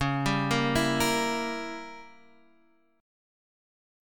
C#dim7 Chord